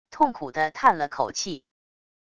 痛苦地叹了口气wav音频